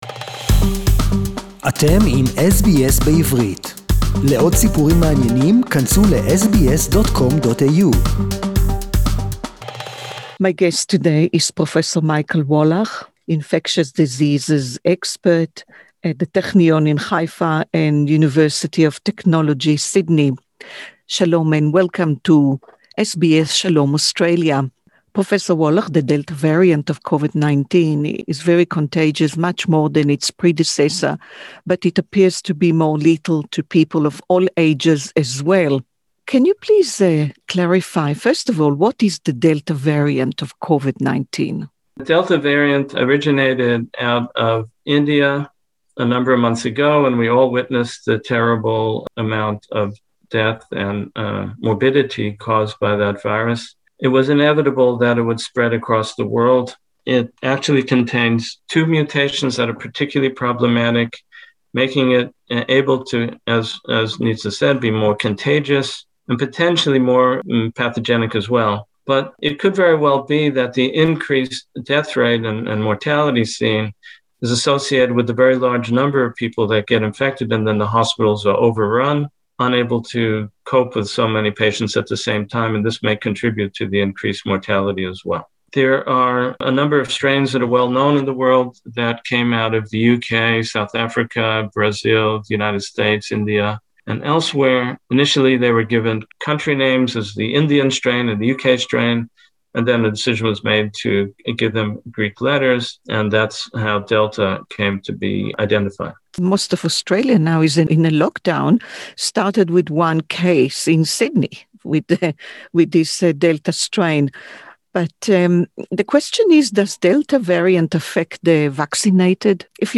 Interview in English Share